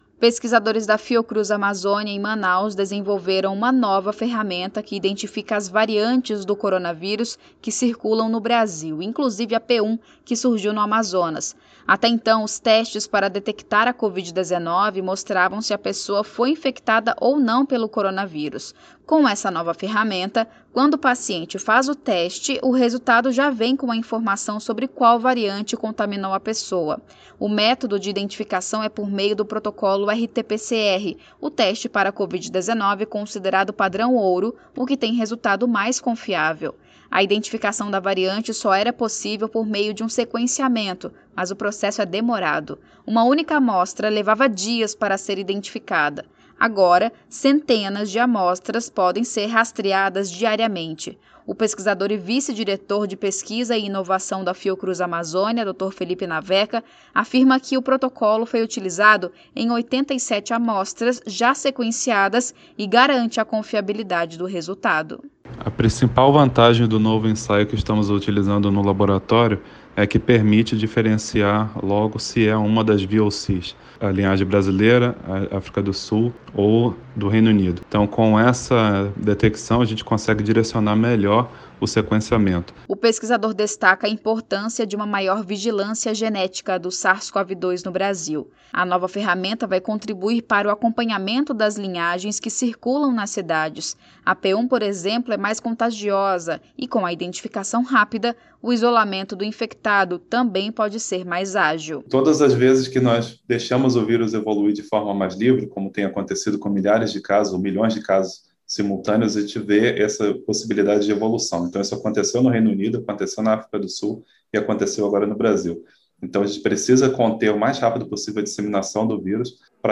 Entenda na reportagem: